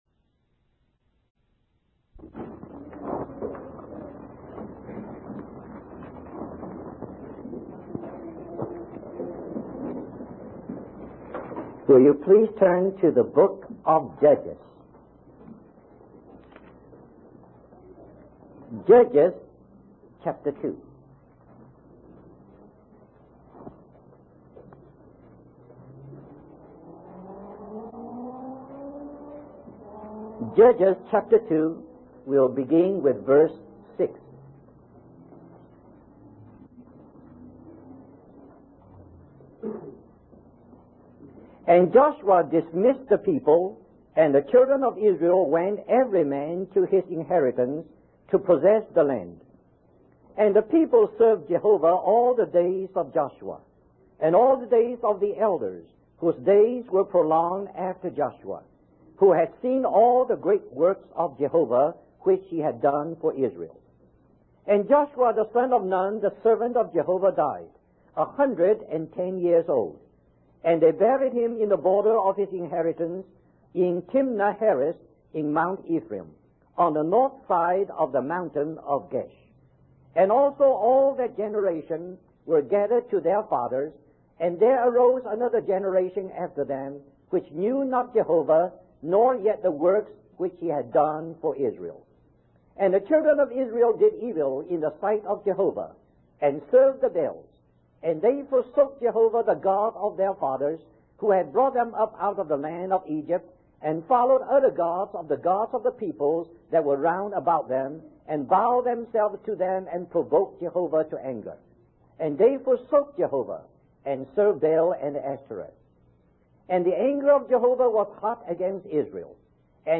In this sermon, the speaker reflects on the current state of God's people and compares it to the time of the judges in the book of Judges. He expresses concern about the division and mistreatment among believers, drawing parallels to the Israelites' behavior during that time.